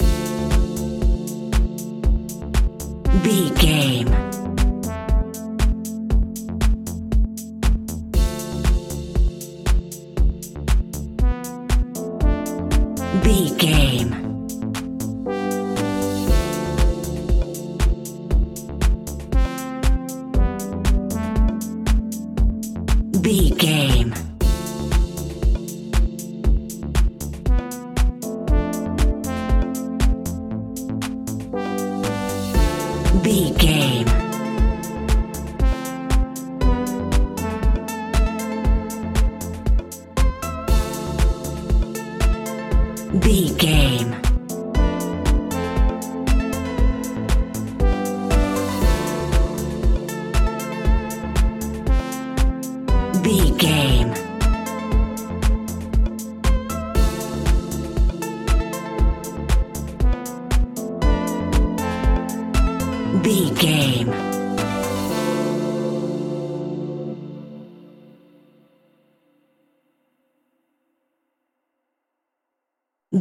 Aeolian/Minor
D
groovy
peaceful
smooth
drum machine
synthesiser
electro house
instrumentals
synth leads
synth bass